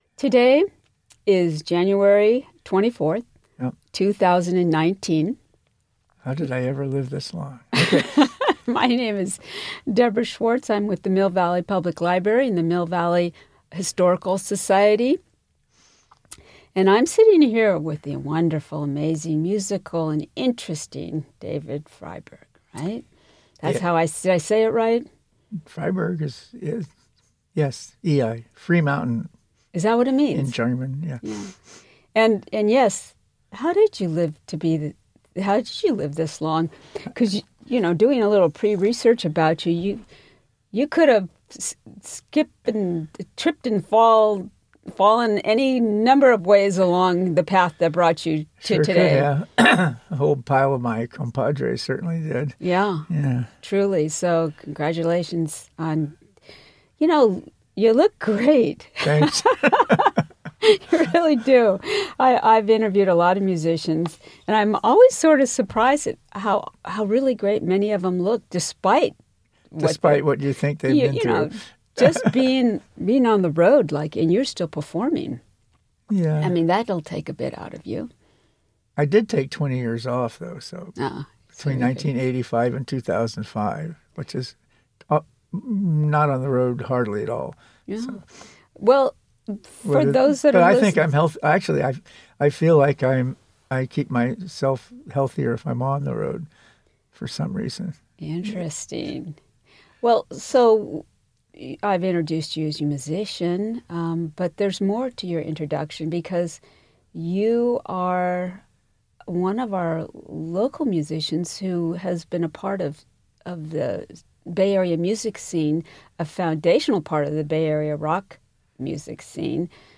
Title Oral History of David Freiberg Collection Oral History Scope & Content In this oral history, David Freiberg, a key figure in the Bay Area music scene from the 1960s onward, recounts his life, career, friends, and family.